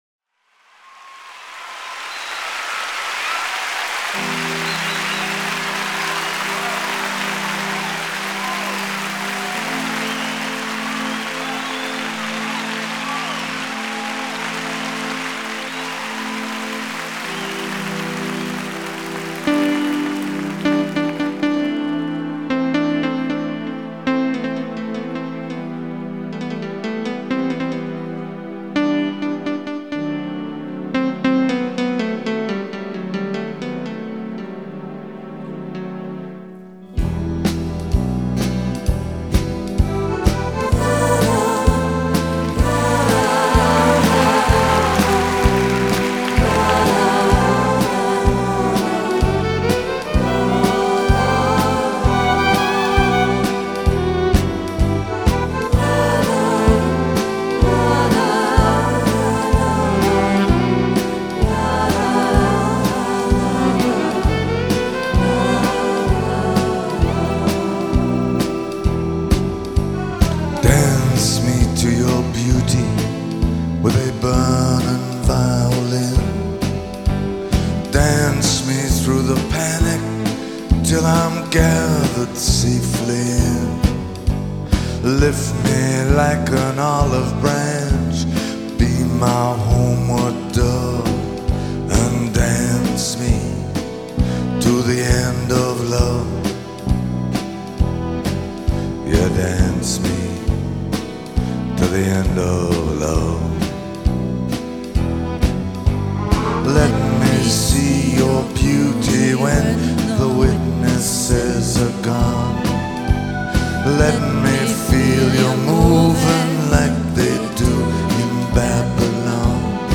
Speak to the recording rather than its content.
Live.